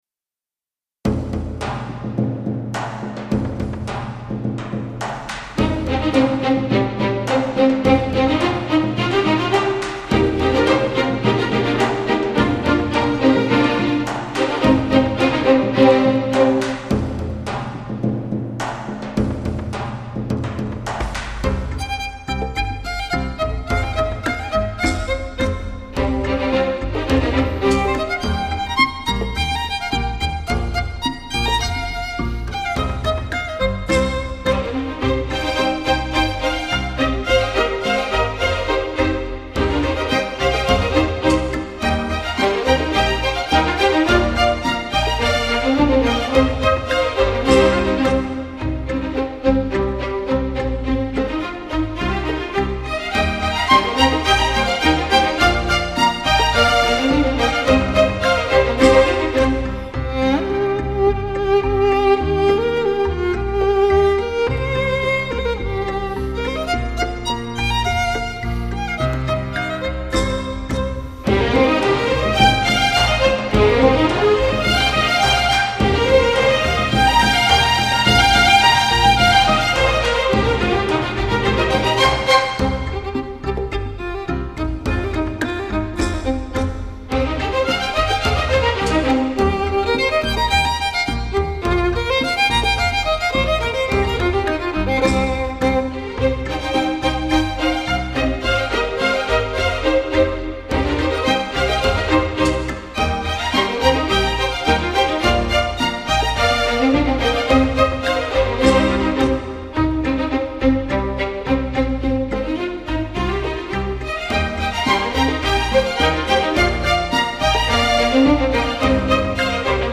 发烧小提琴
小提琴领奏、独奏